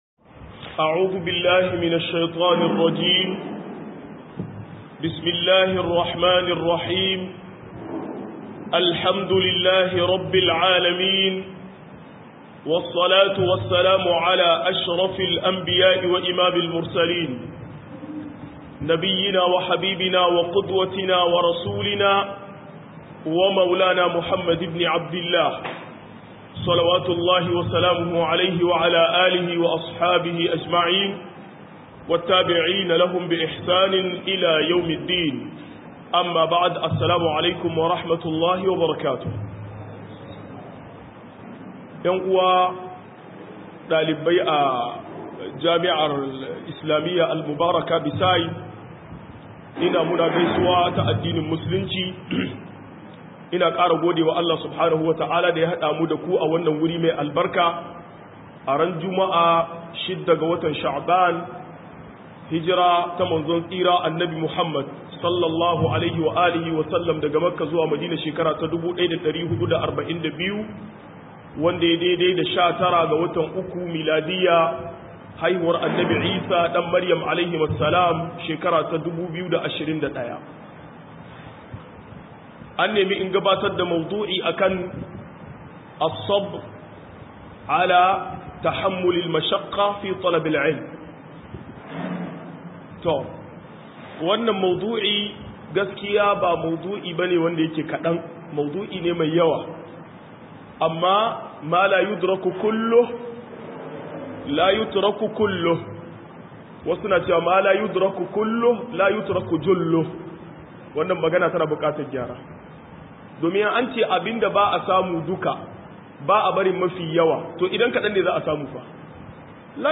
105-Dukufa kan neman ilimi - MUHADARA